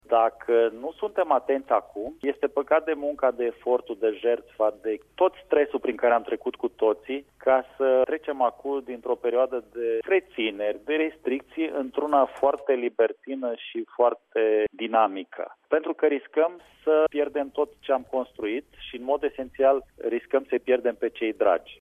Însă numărul de restricții care ar putea fi eliminate treptat după data de 15 mai depinde foarte mult de felul în care le vom respecta până atunci, a spus ministrul Vela: